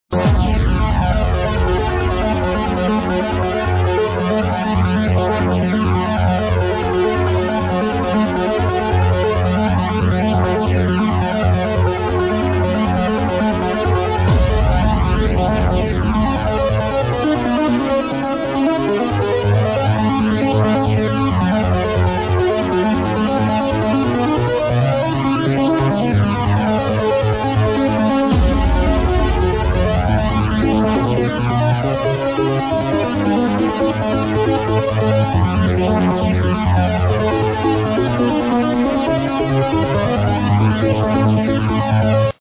trance ID
ID this older trance track plz
but whoevers set u ripped it from, they weely liked that flanger